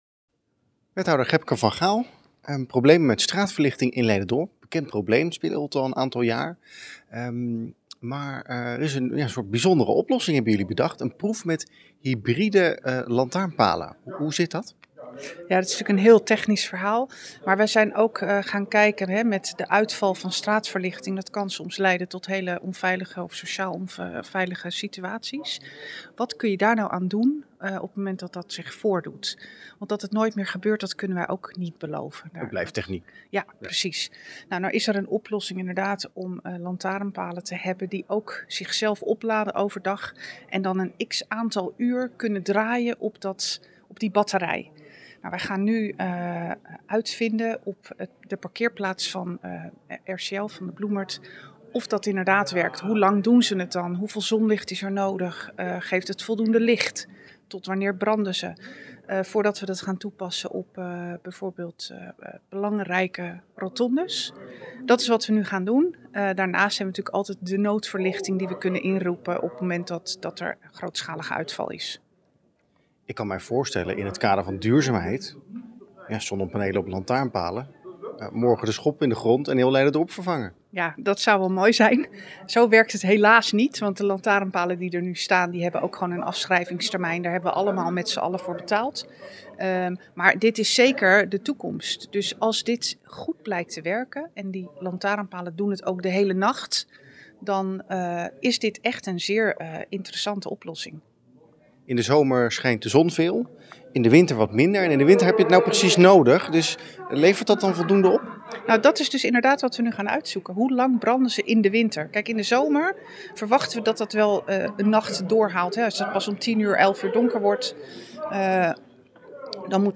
in gesprek met wethouder Gebke van Gaal over hybride straatverlichting.